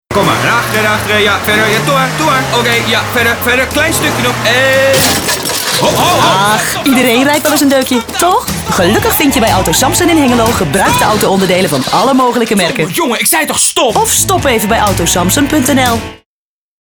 AS_radiocommercial.mp3